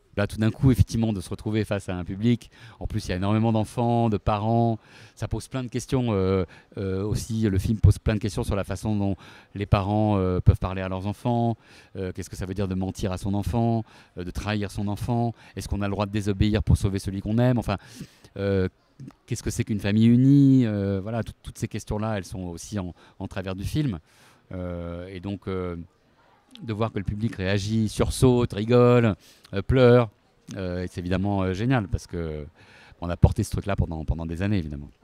Extrait d’une interview avec le réalisateur Gilles de Maistre.
interview-gilles-de-maistre-audio.m4a